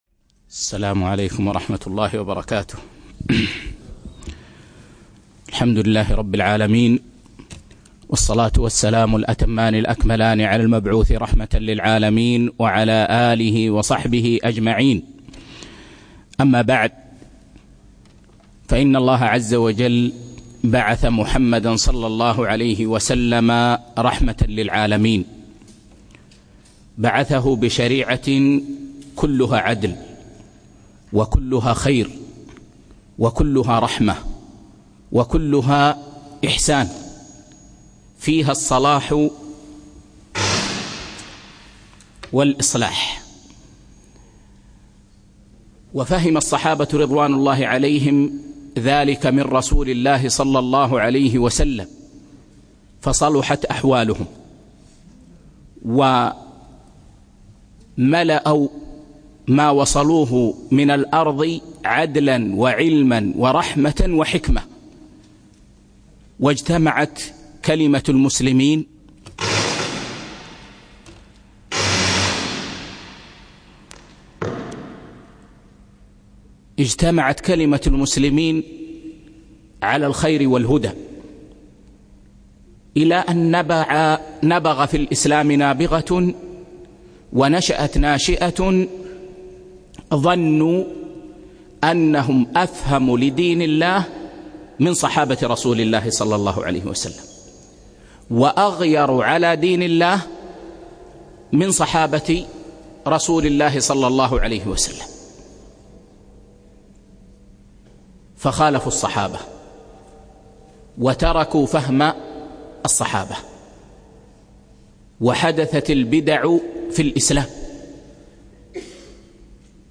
4- فقه المعاملات المالية (2) - الدرس الثالث